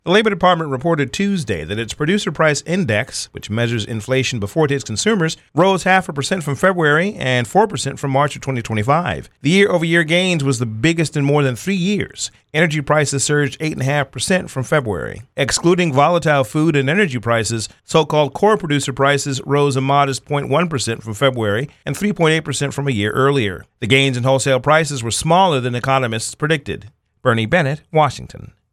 Salem Radio Network News Tuesday, April 14, 2026